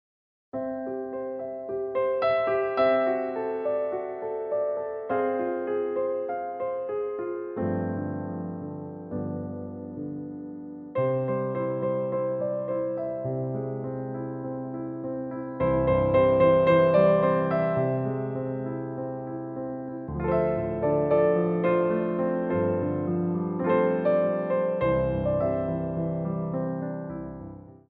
Theme Songs from Musicals for Ballet Class
Piano Arrangements
Pliés 2
4/4 (8x8)